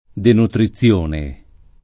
[ denutri ZZL1 ne ]